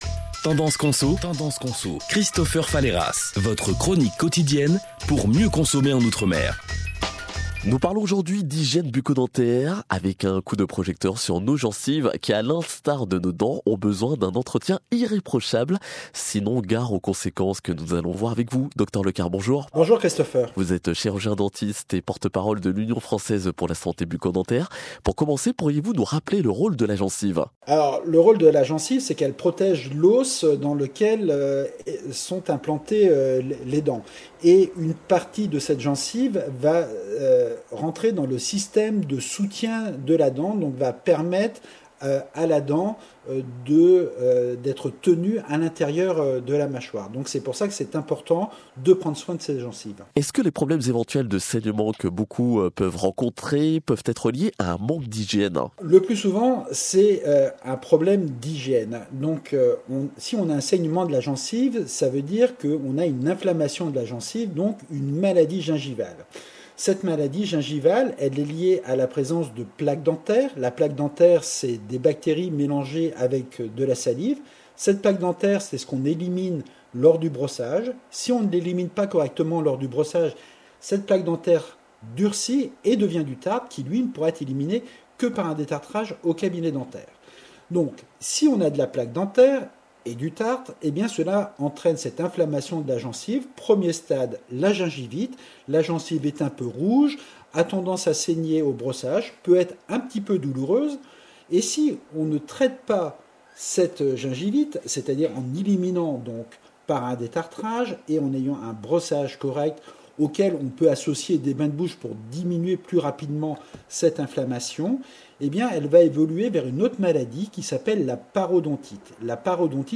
En paroles l’entretien